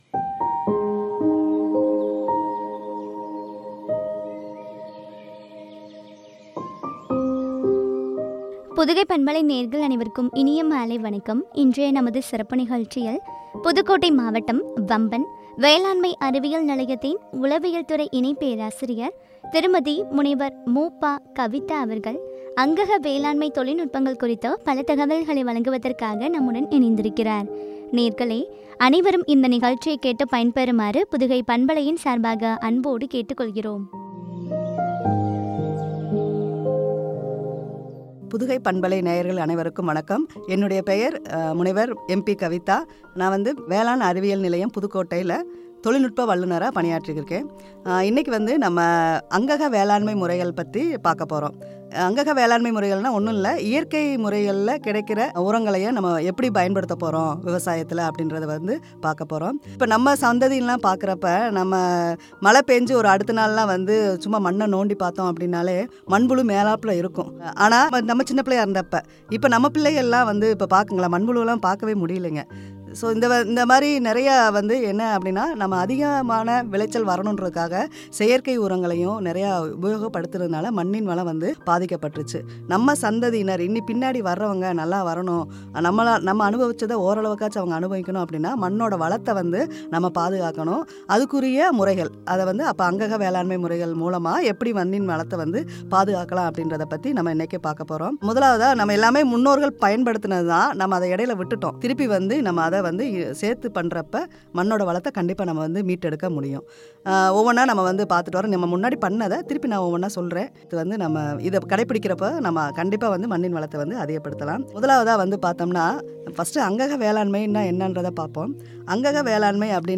அங்கக வேளாண்மை தொழில்நுட்பங்கள் பற்றிய உரையாடல்.